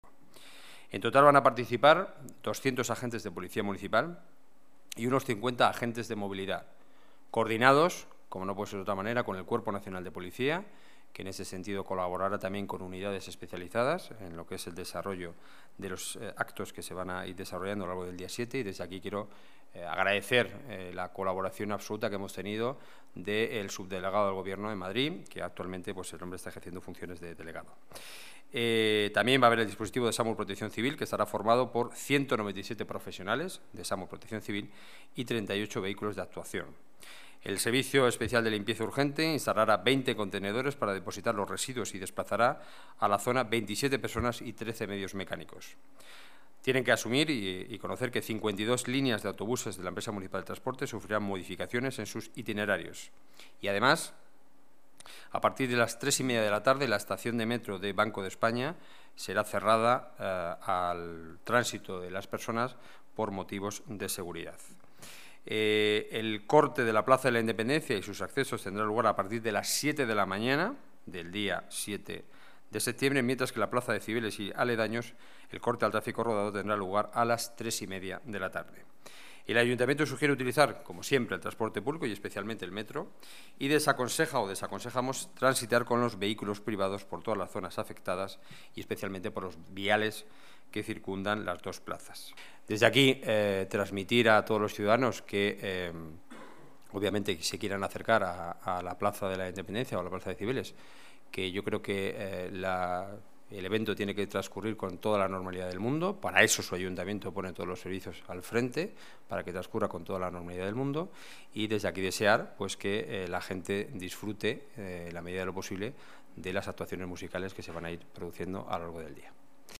Nueva ventana:Declaraciones portavoz Gobierno municipal y delegado de Seguridad y Emergencias, Enrique Núñez: dispositivo Ayuntamiento para la fiesta olímpica